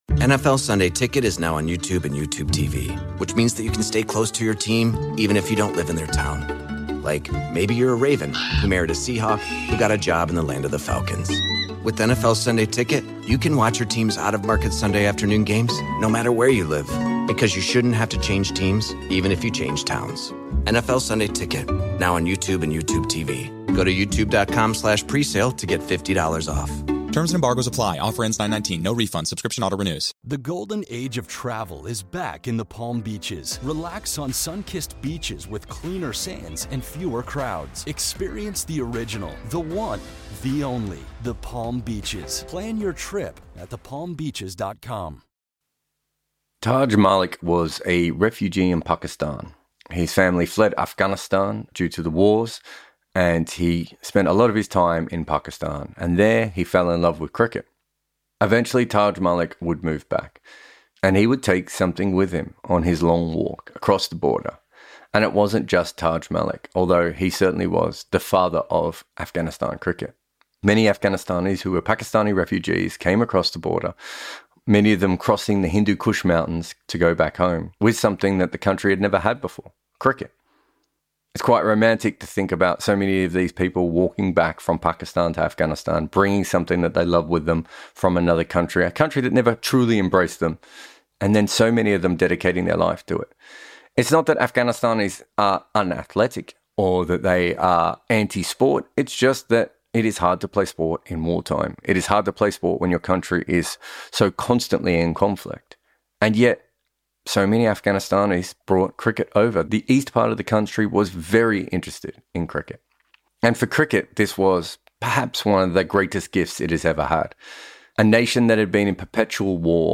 This week we’re adding a bonus episode on Afghanistan, the most likely team to beat England in the next few years. This is not a standard Double Century, it’s not a written and narrated piece.